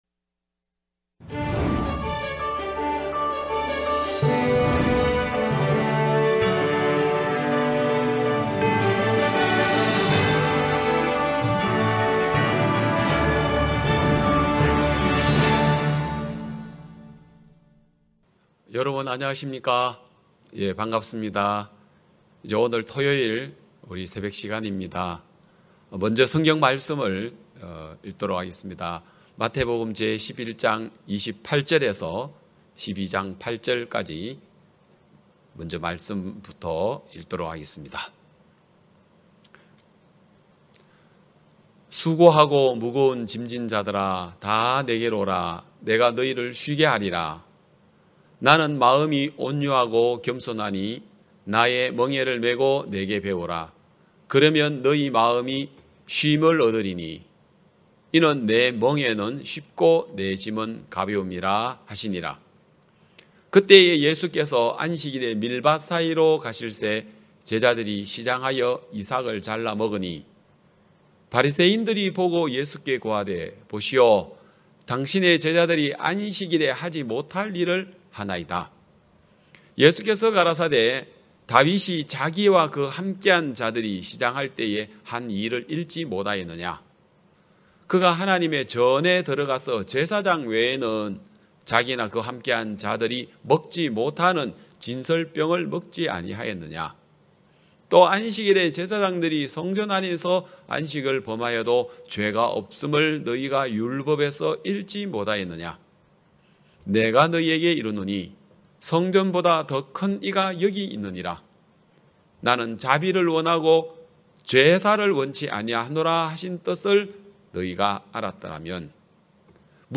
매년 굿뉴스티비를 통해 생중계 됐던 기쁜소식 선교회 캠프의 설교 말씀을 들어보세요.